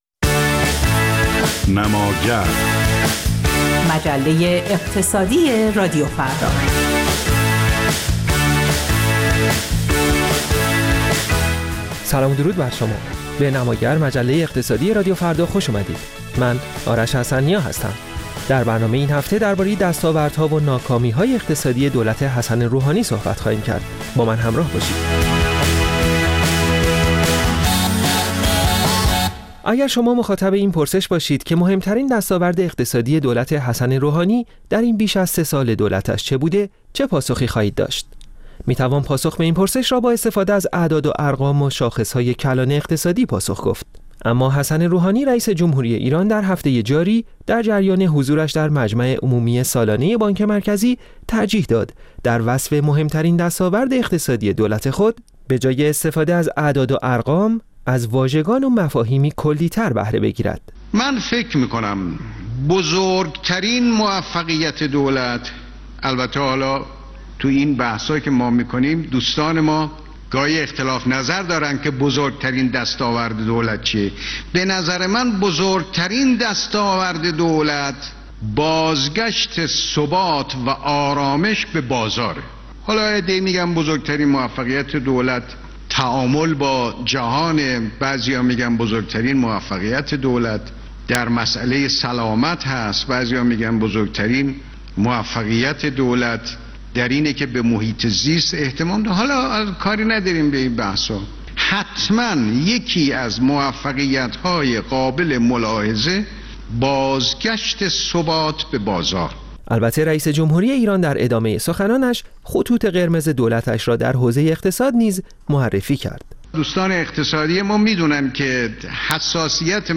«نماگر»، مجله اقتصادی رادیو فردا، نمایی است از رویدادهای هفتگی اقتصاد ایران و جهان.